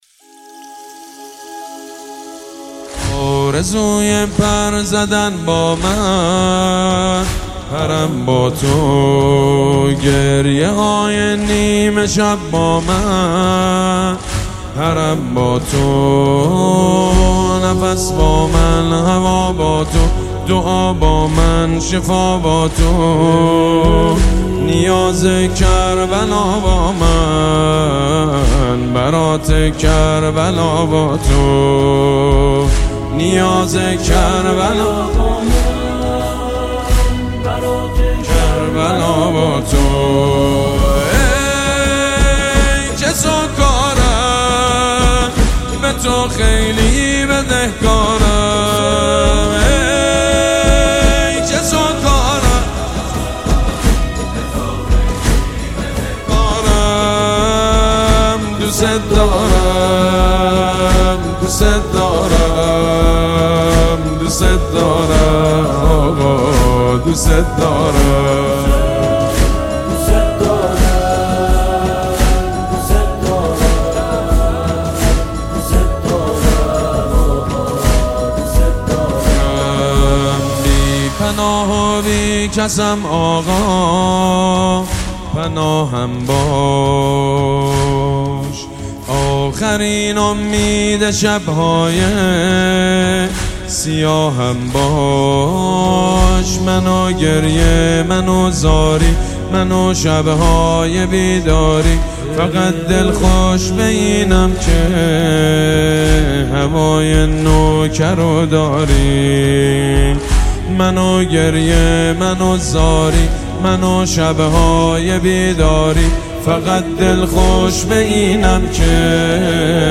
با نوای مداحان مشهور کشوری و بین المللی...